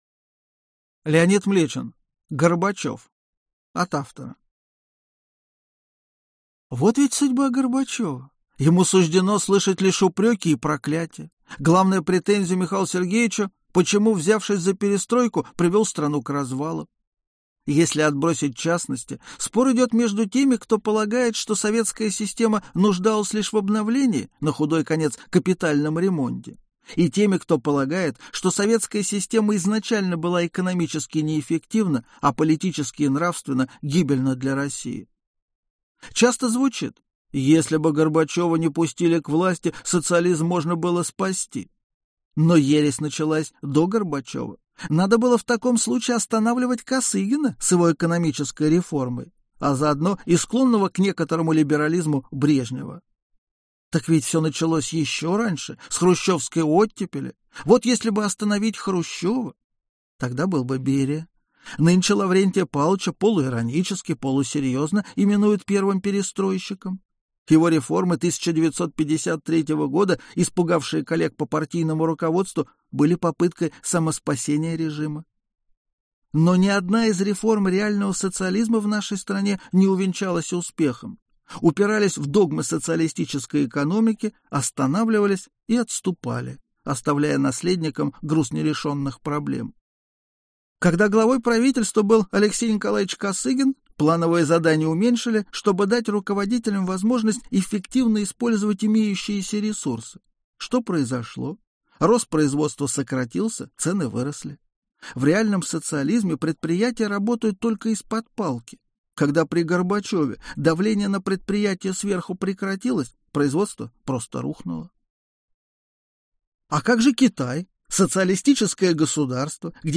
Аудиокнига Горбачёв | Библиотека аудиокниг
Aудиокнига Горбачёв Автор Леонид Млечин Читает аудиокнигу Леонид Млечин.